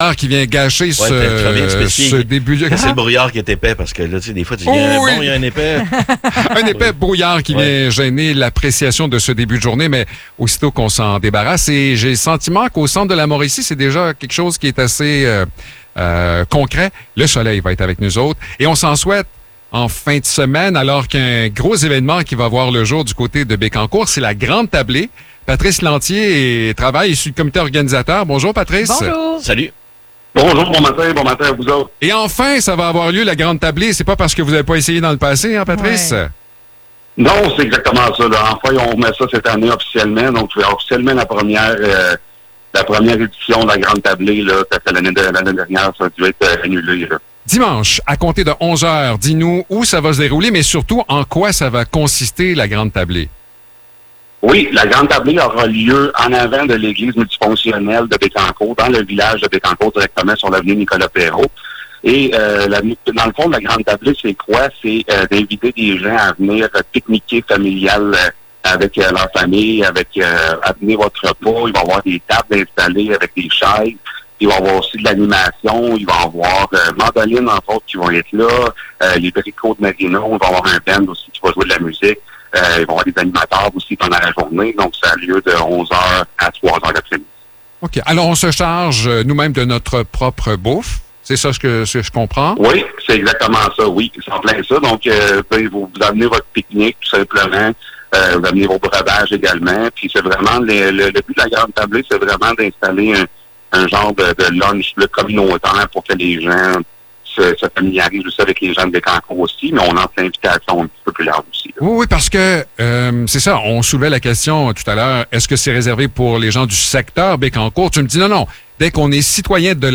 Entrevue pour la Grande Tablée de Bécancour